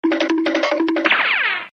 Cartoon-run Bongo Sound Button - Free Download & Play